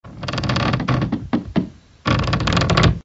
SZ_DD_dockcreak.ogg